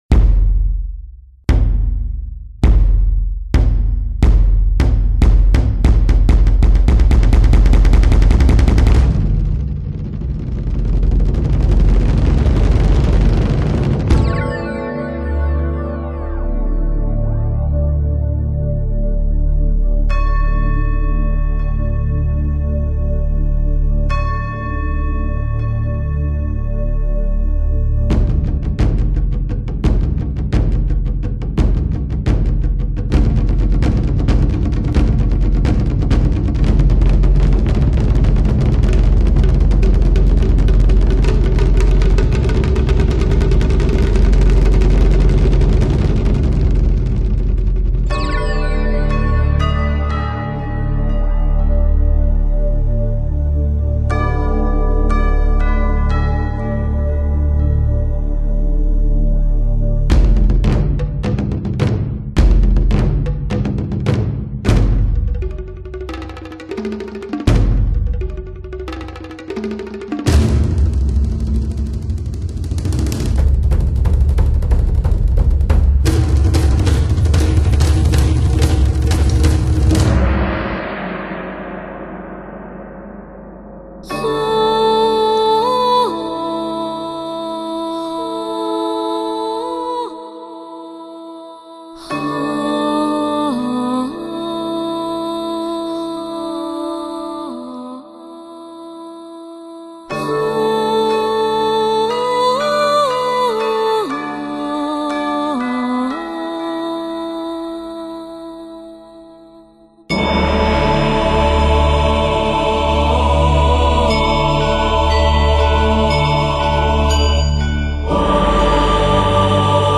作品无论在配器、和声等方面都会让你有全新的感受。
【 专辑特点 】 中国第一张本土歌新世纪(NEW AGE)发烧天碟 经典的曲目，完美的演唱，每个细节都表现的完美无暇。
全碟全都使用了顶级数码录音和还原技术及设备， 各项指标都是发烧无比。